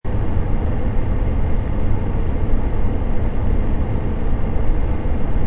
computer-fan-84719.mp3